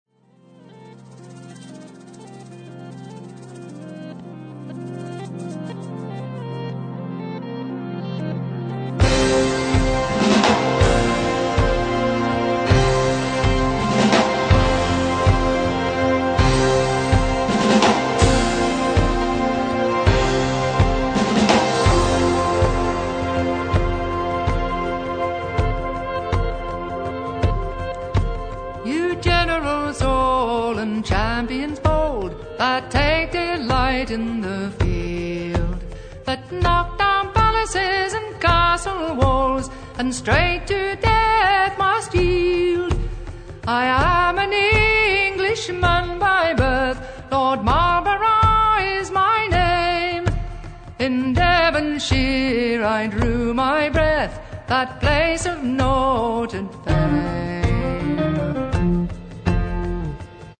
First part, 1:00 sec, mono, 22 Khz, file size: 297 Kb.